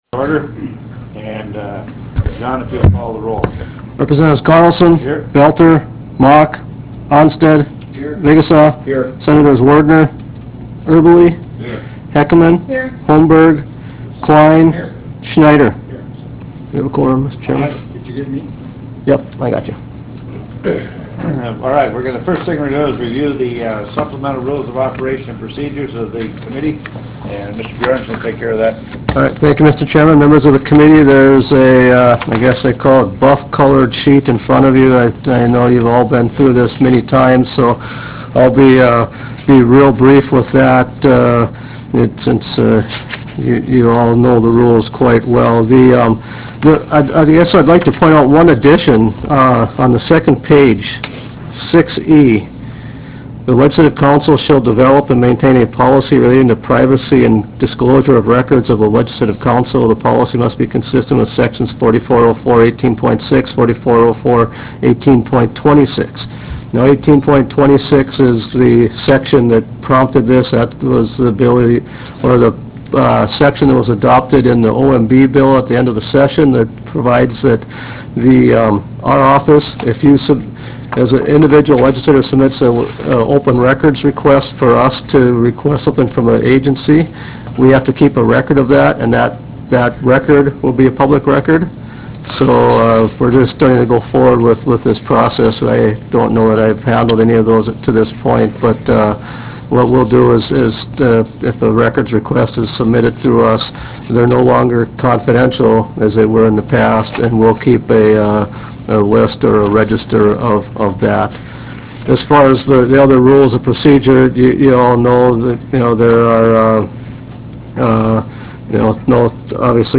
Harvest Room State Capitol Bismarck, ND United States